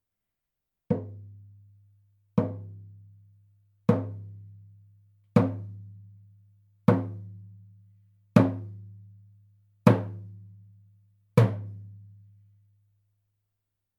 ネイティブ アメリカン（インディアン）ドラム NATIVE AMERICAN (INDIAN) DRUM 14インチ（deer 鹿）
ネイティブアメリカン インディアン ドラムの音を聴く
乾いた張り気味の音です